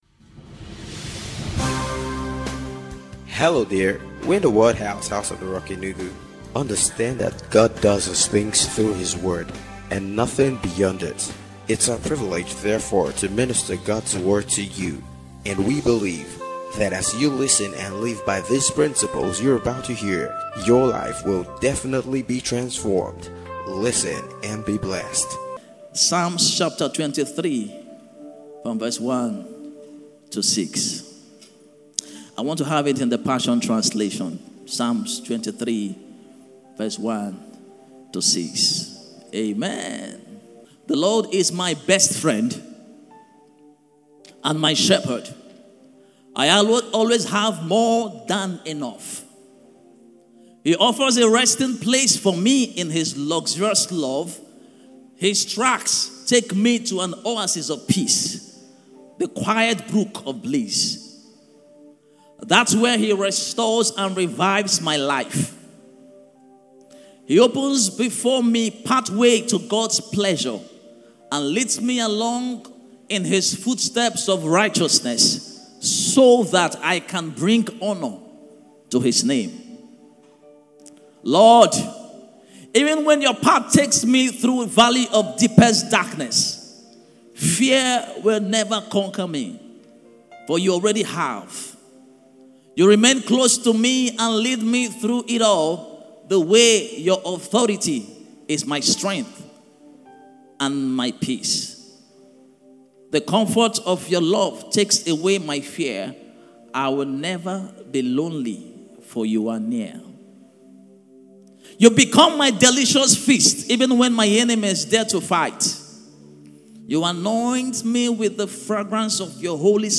GOODNESS AND MERCY SHALL FOLLOW ME - FRESHDEW SERVICE